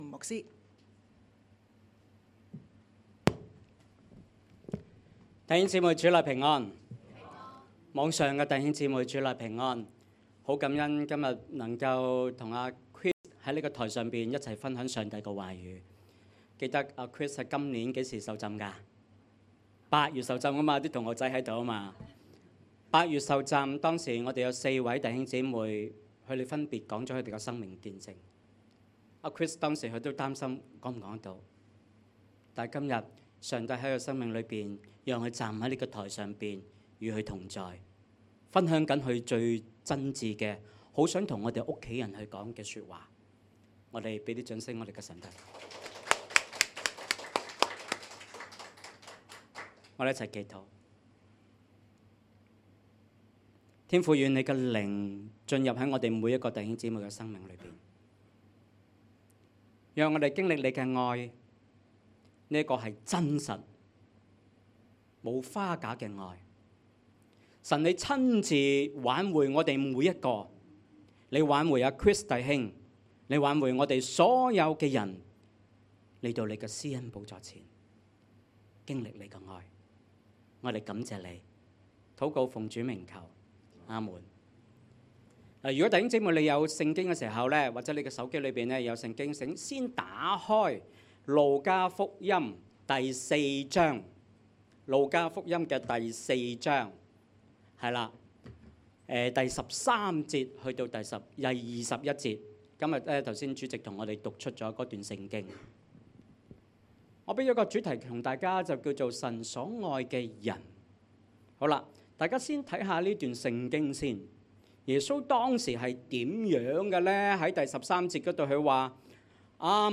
Sermon_2025_1116.mp3